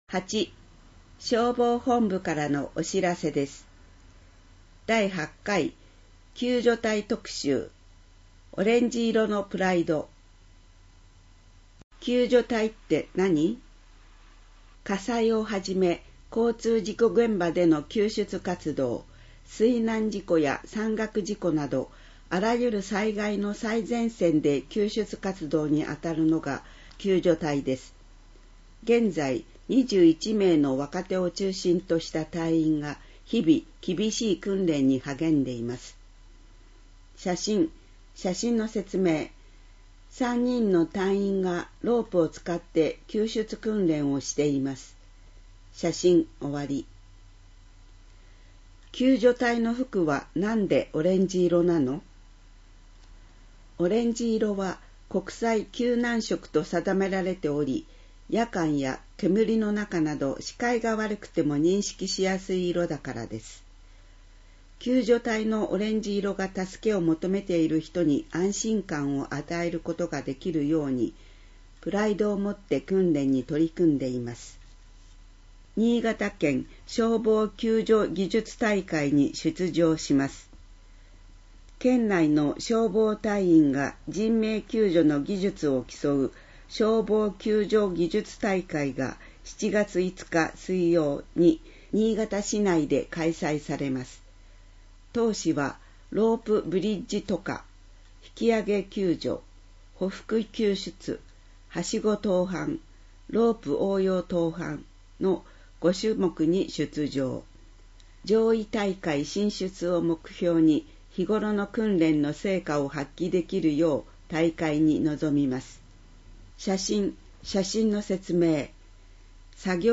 市では、視覚に障がいのある方向けに、ボランティア団体「うぐいす会」の皆さんのご協力により、広報あがのを音声訳したCDを作成し、希望する方に配付しています。